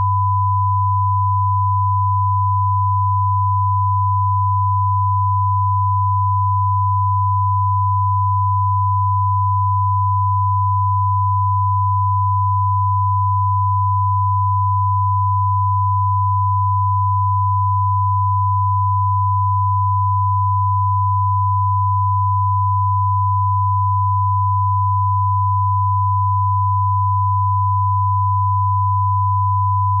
These test files contain -10dBFS 1kHz tone on the left channel and 100Hz tone on the right channel.